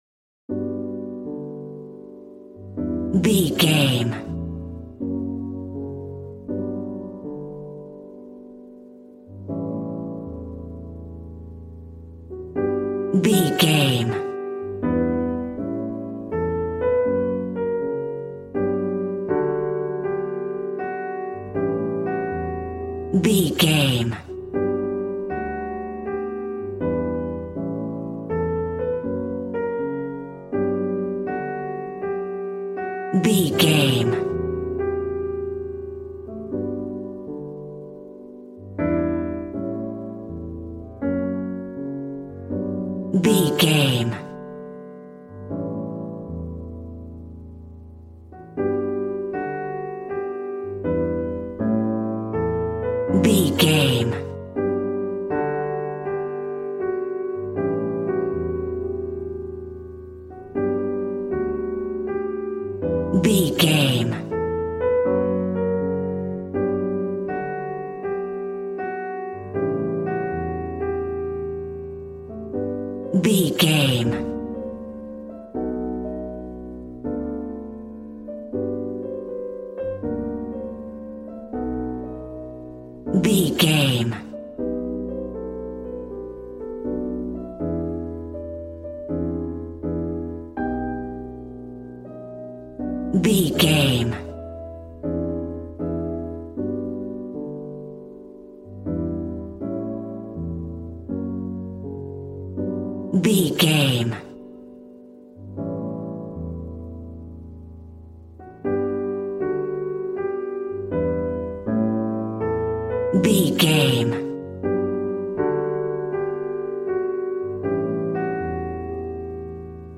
Smooth jazz piano mixed with jazz bass and cool jazz drums.,
Aeolian/Minor
drums